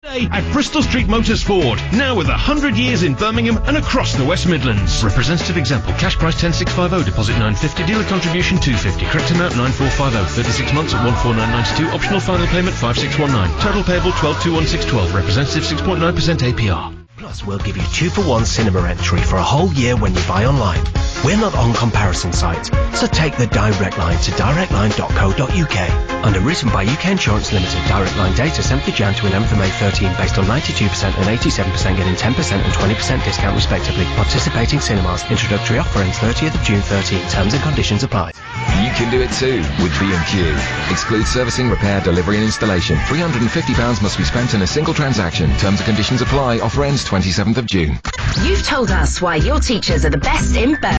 These 'ad ends' are all culled from the same ad break in June 2013.